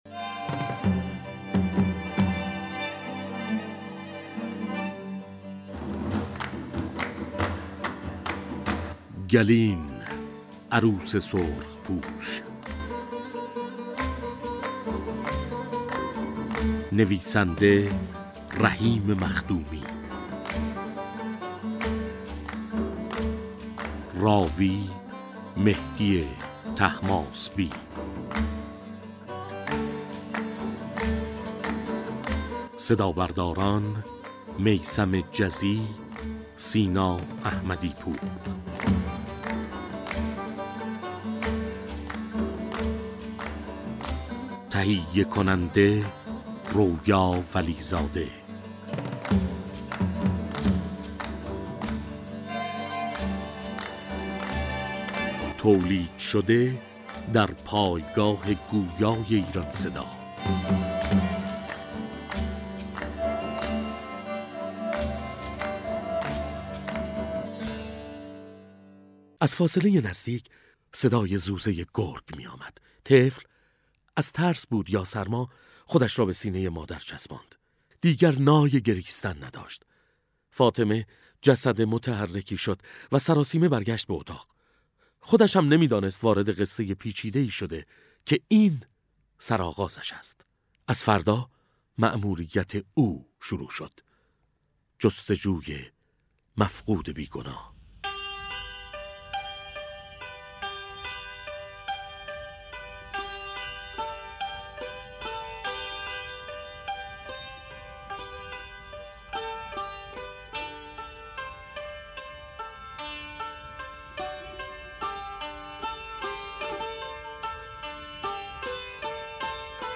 «گلین» نام روستایی در حوالی سنندج است که سال 1360 حوادث تلخ و خونباری را تجربه می کند و گوشه ای از تاریخ رشادت های مردمان کردستان را در دل خود جای داده است. این کتاب توسط «ایران صدا» به کتاب گویا تبدیل شده و نسخه صوتی آن برای مخاطبان نوید شاهد در ادامه مطلب قابل دسترس است.